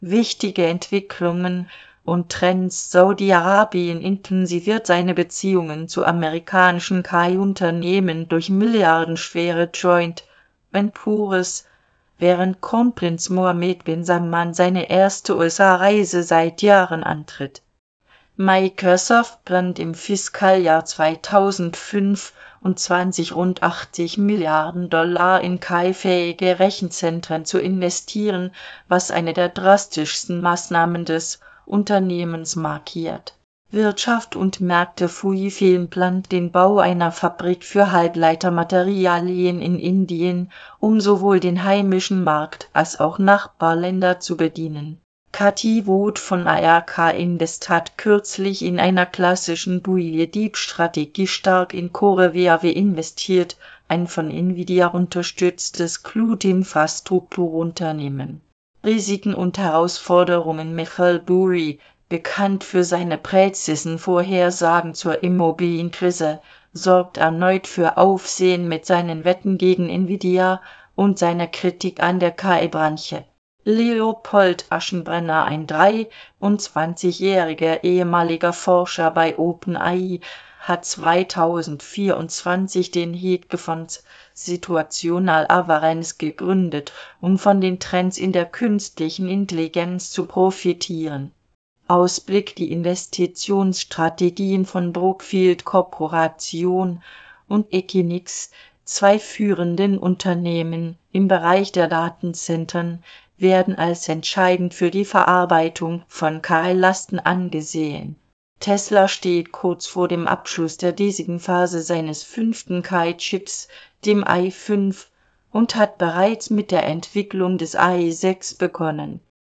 Vorlesen (MP3)